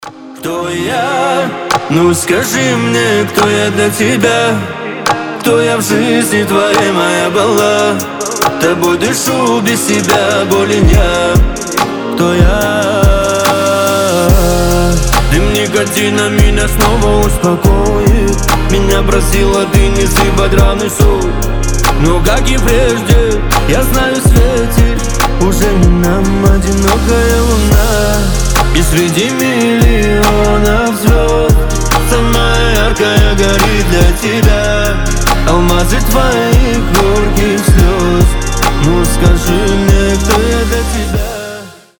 мужской голос
красивые
лирика